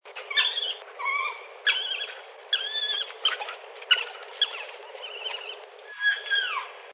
Hiénakutya